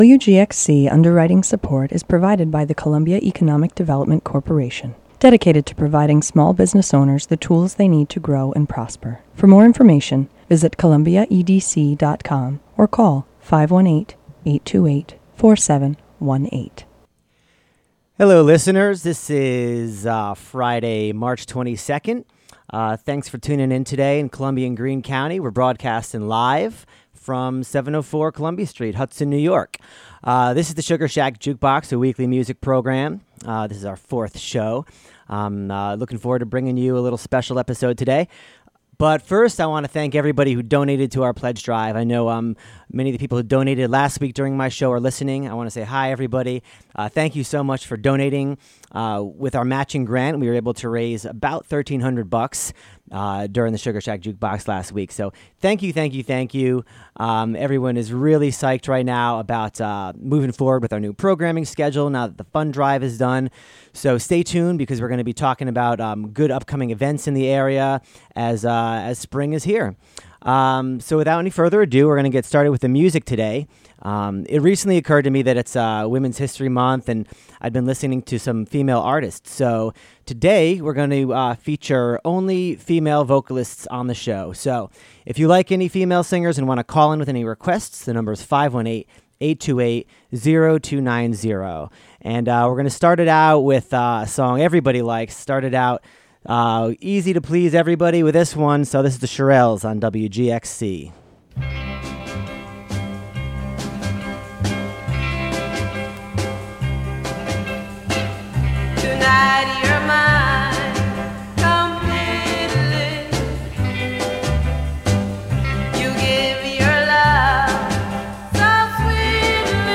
The Sugar Shack Jukebox celebrates Women's History Month by featuring an eclectic mix of female vocalists.